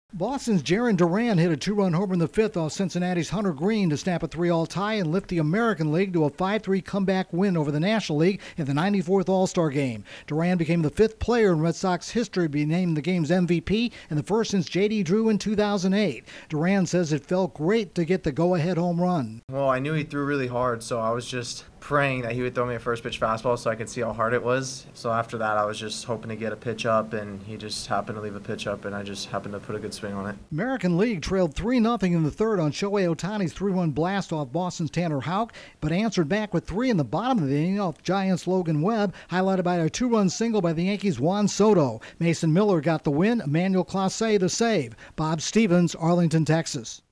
The Red Sox' Jarren Duran snags the Ted Williams trophy, hitting for the American League at Tuesday's All-Star Game in Texas. Correspondent